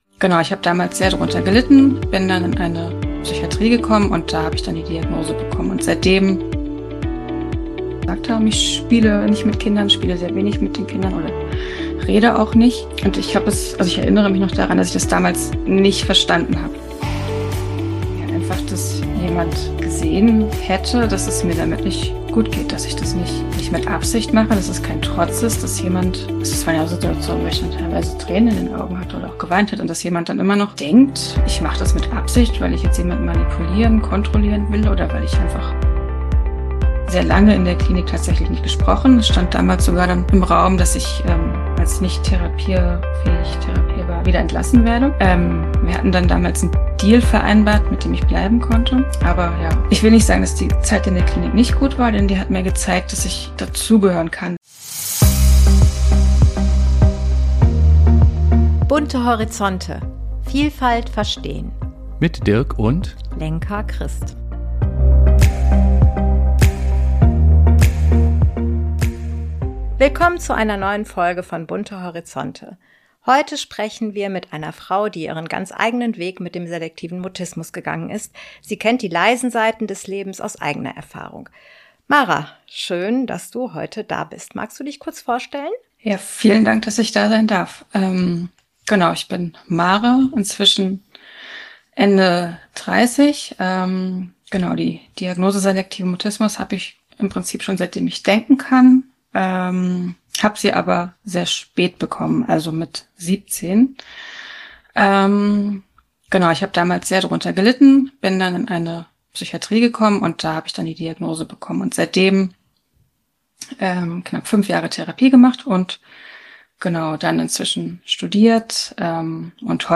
Ein sensibles, stärkendes Gespräch für Fachkräfte, Eltern, Betroffene – und alle, die besser verstehen möchten, was hinter dem Schweigen steckt.